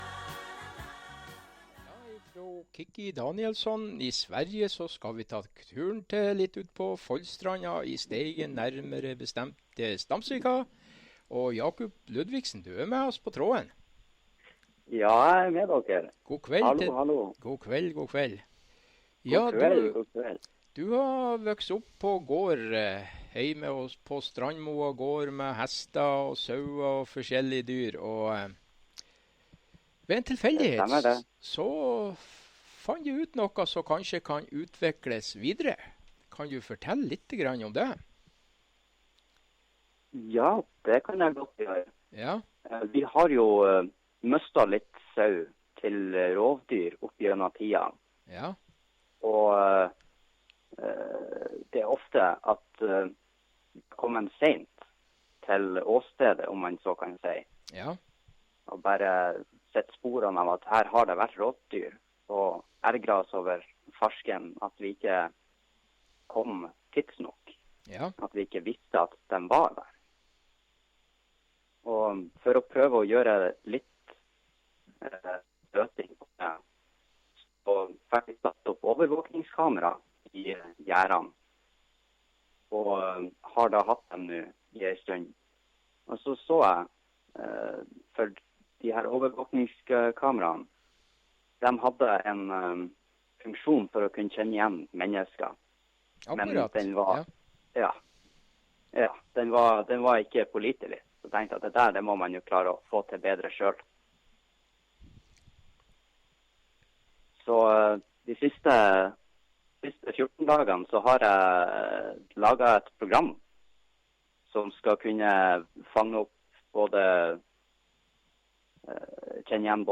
intervju-rns.opus